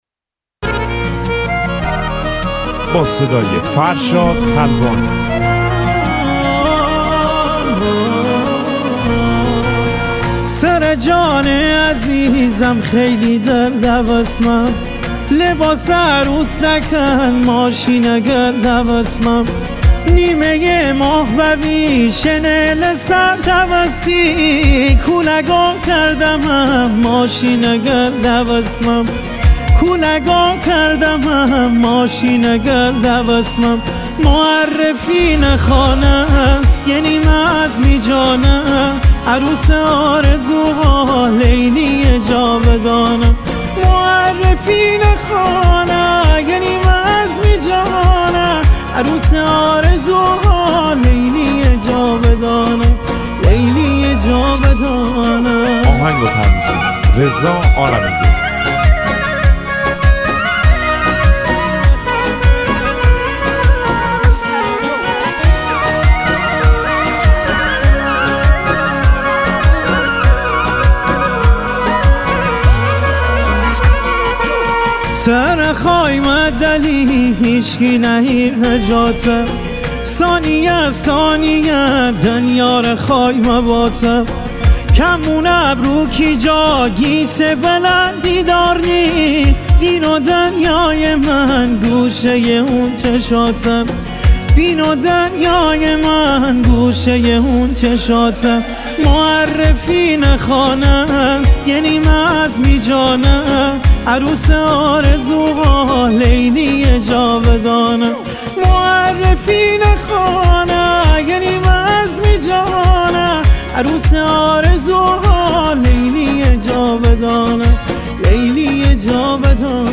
موزیک مازندرانی